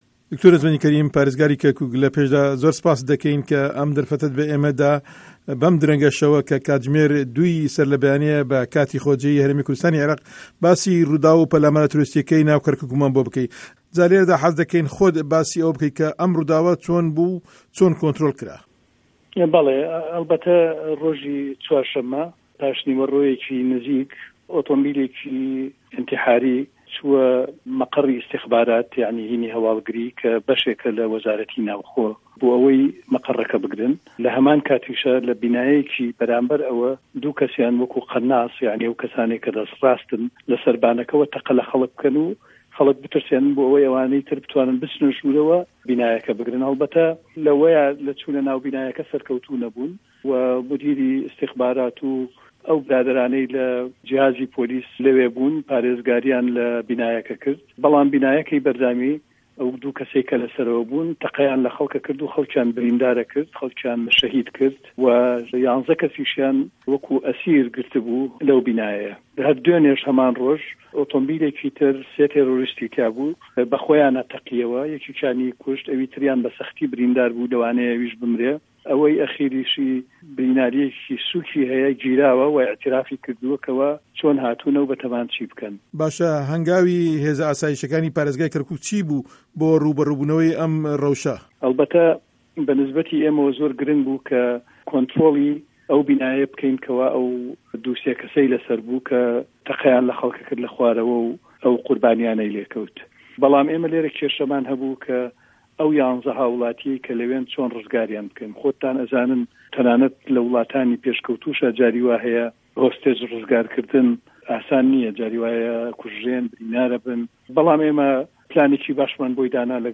Hevpeyvîn bi Dr. Necmedîn Kerîm re